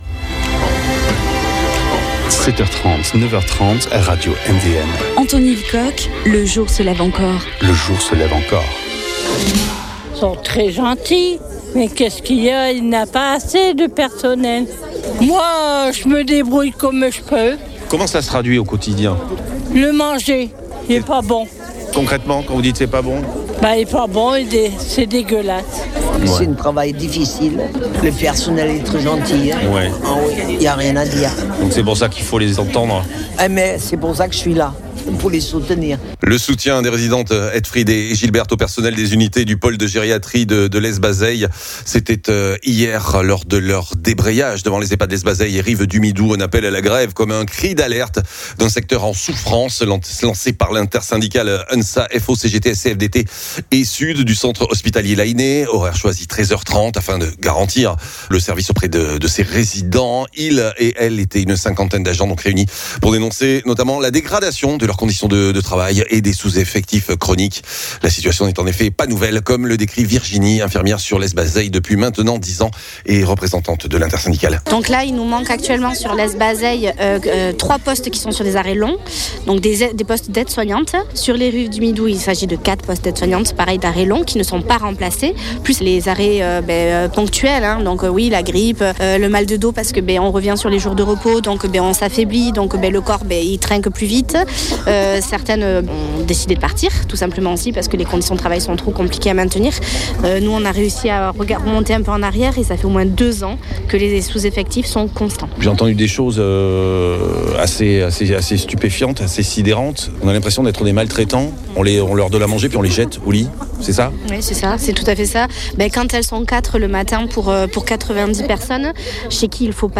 3 agents pour 90 résidents à Lesbazeilles, de 2 à 3 pour 40 au Midou; le personnel du pole gériatrie, s’est mobilisé hier à 13h30 devant leurs établissements à l’appel de l’intersyndicale l’Unsa, FO, CGT, CFDT et SUD) de l’hôpital de Mont de Marsan pour dénoncer les sous – effectifs au sein des deux services et la dégradation de leurs conditions de travail. Témoignages.